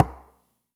Knock16.wav